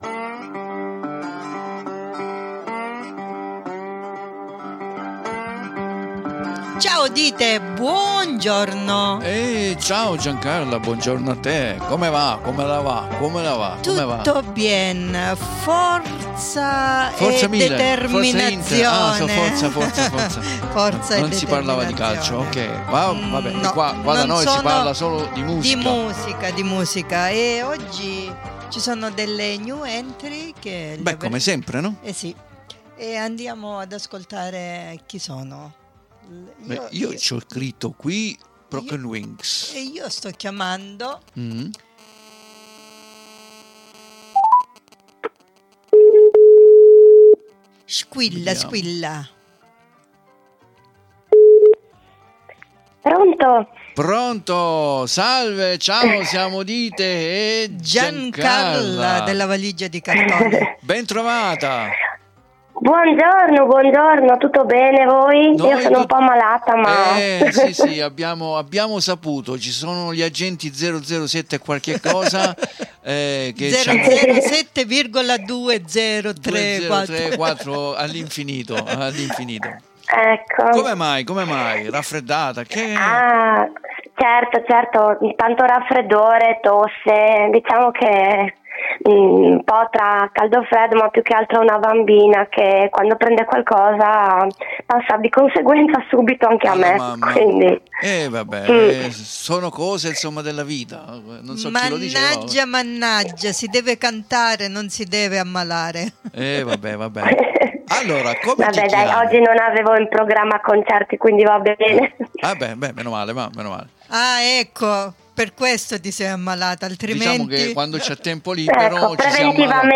CON LA SUA VOCE CANGIANTE E OGGI UN PÓ RAFFREDDATA
93851_INTERVISTA_Broken_Wings.mp3